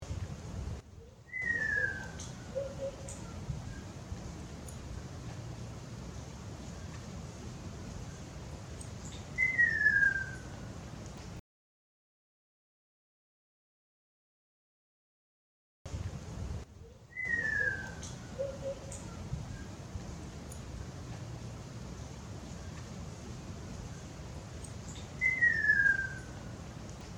次の鳴き声はどの鳥でしょう。
A ．サンショウクイ　B．アカショウビン
akasyoubin.wav